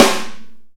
normal-hitclap.mp3